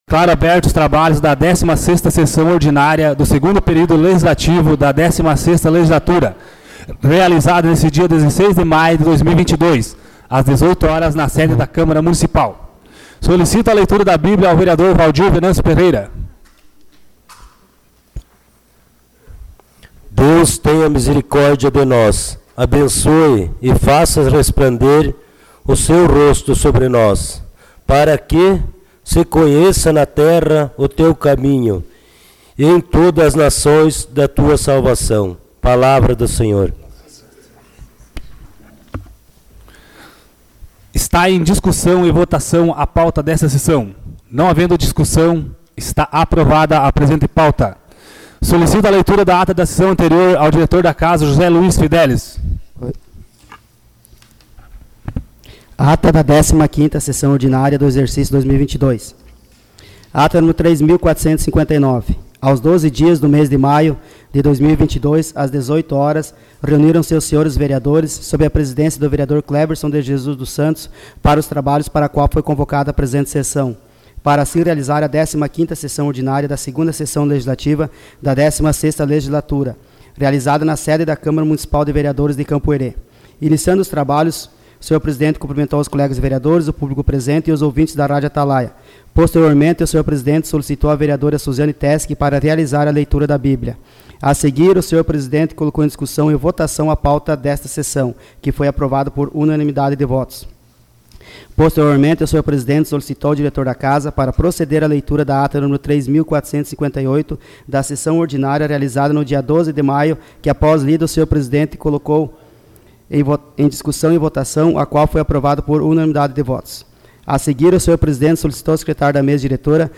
Sessão Ordinária dia 16 de maio de 2022